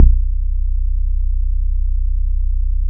BASS 3.wav